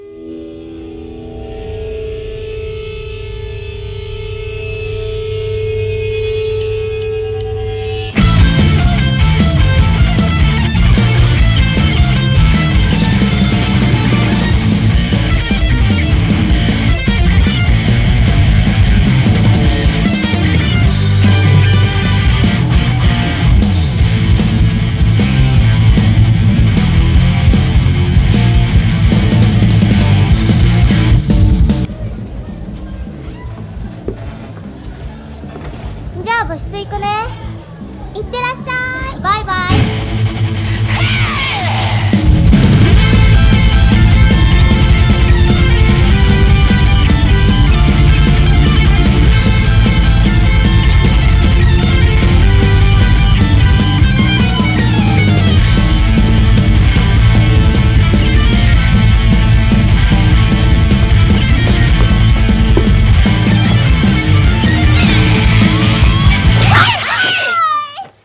BPM153-153
Audio QualityPerfect (Low Quality)